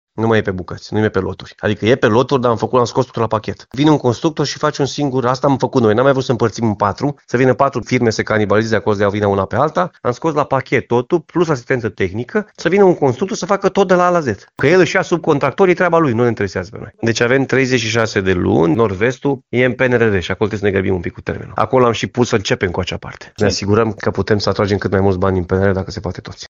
Președintele Consiliului Județean Timiș, Alfred Simonis, spune că proiectele care vizau reabilitarea castelului au fost unificate pentru desemnarea unui singur constructor.